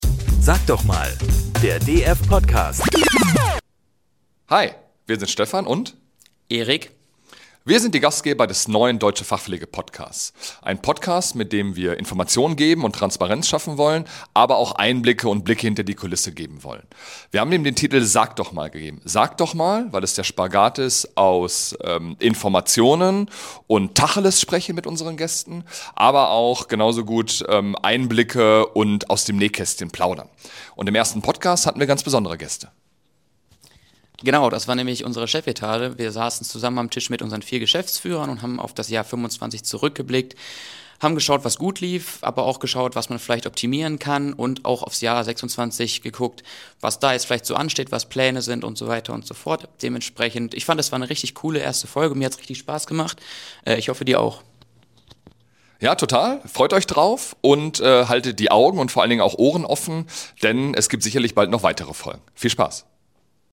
An diese beiden Stimmen könnt ihr euch schon mal gewöhnen!